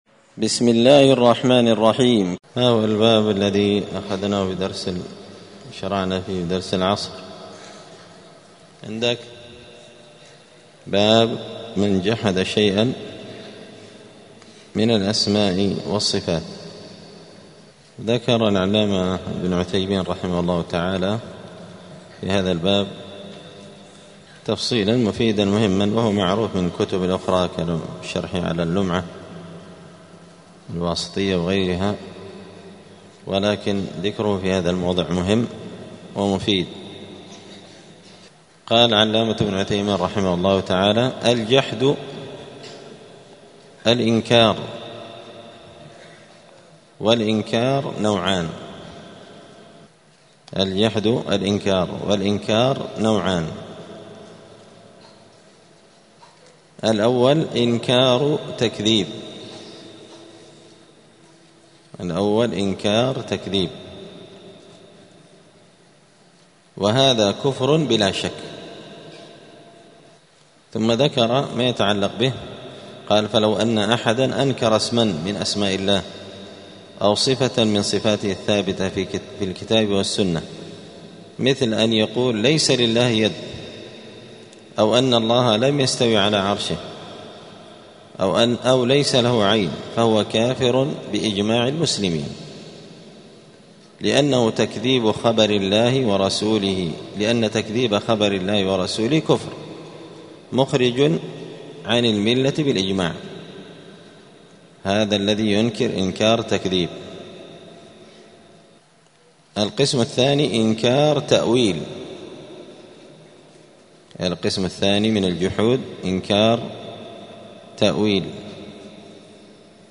دار الحديث السلفية بمسجد الفرقان قشن المهرة اليمن
*الدرس الخامس عشر بعد المائة (115) تابع لباب من جحد شيئا من الأسماء والصفات*